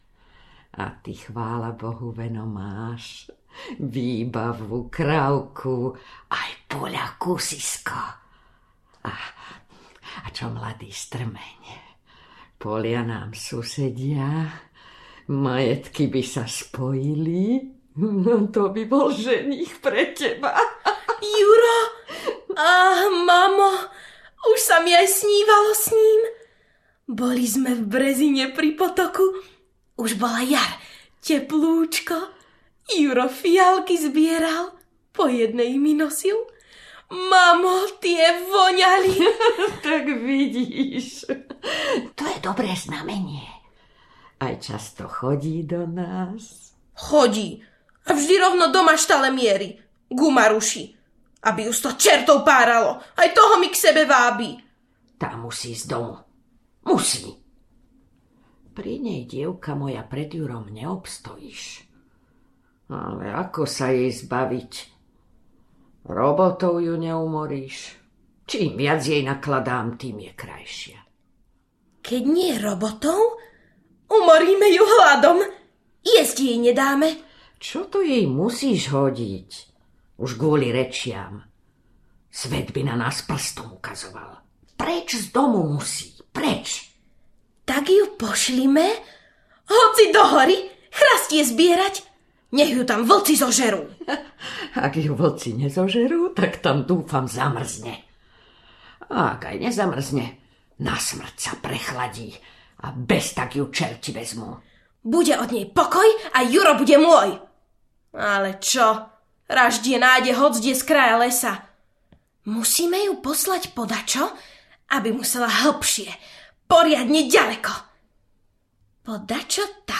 O dvanástich mesiačikoch audiokniha
Ukázka z knihy